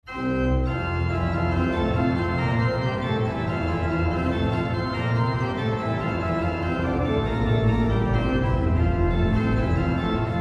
Der Klang der Ehrlich-Orgel in der Stadtkirche hat seine Wurzeln im mainfränkischen Orgelbau der Barockzeit. In seiner Farbigkeit wirkt er auf heutige Ohren meist auf das erste eher ein wenig fremd und archaisch.